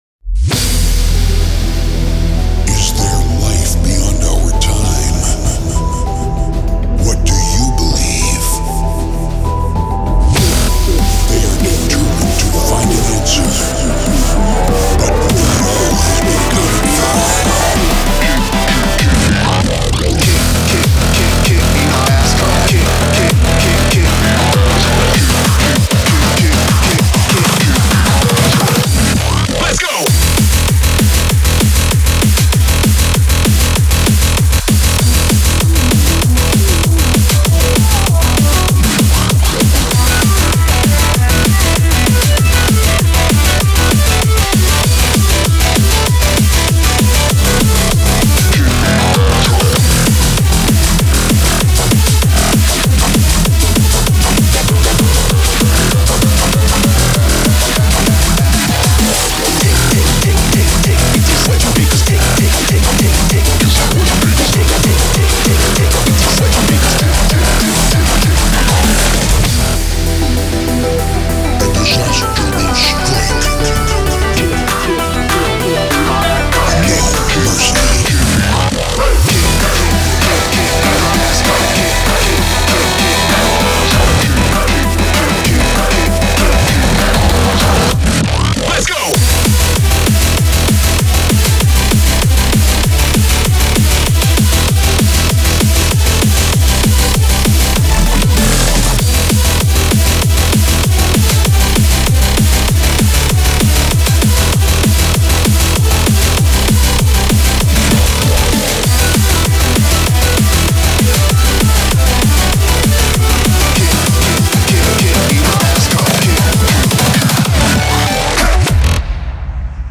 BPM49-195